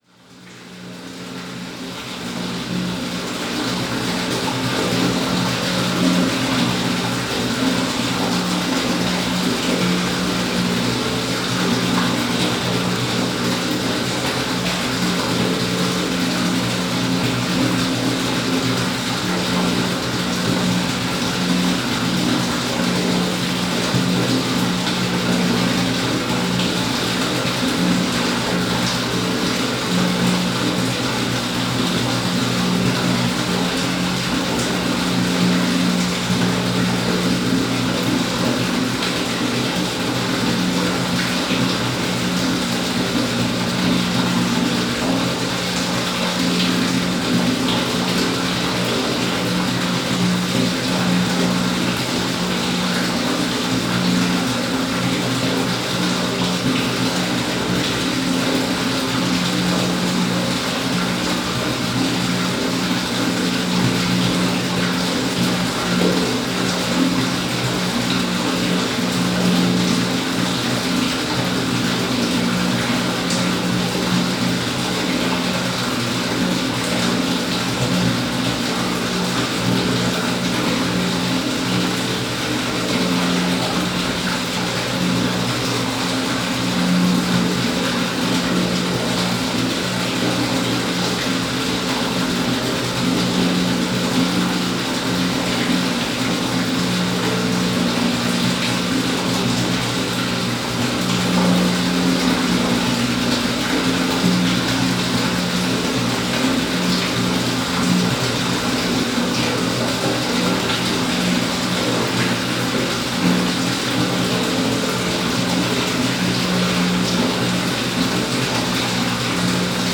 Paisagem sonora de escoamento de água de lago junto ao Museu do Quartzo em Vila Nova do Campo, Campo a 3 Março 2016.
A água do lago junto ao Museu do Quartzo é fria e não respinga.
NODAR.00559 – Campo: Escoamento de água de lago junto ao Museu do Quartzo em Vila Nova do Campo